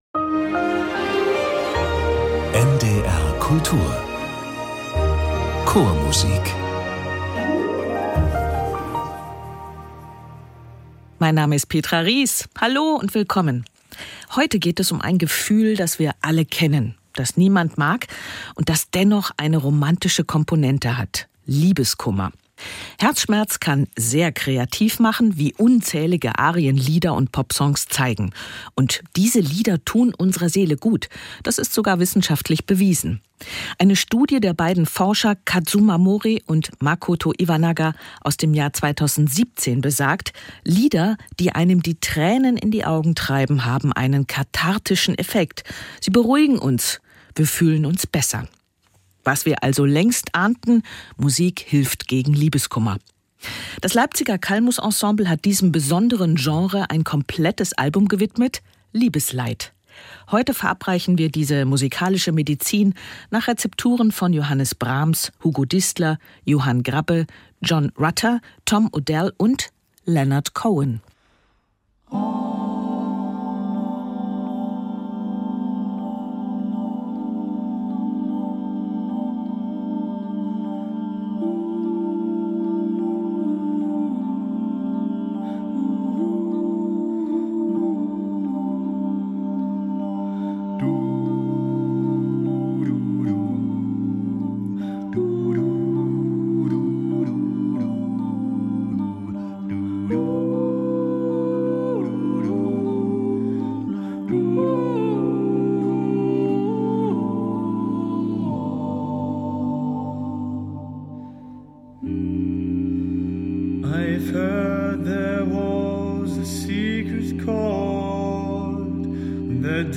Gut gegen Liebeskummer ~ Chormusik - Klangwelten der Vokalmusik entdecken Podcast
Die fünf Stimmen von Calmus singen das Stück in einem Arrangement, das der in Weimar lebende Komponist Juan Garcia speziell für sie geschrieben hat.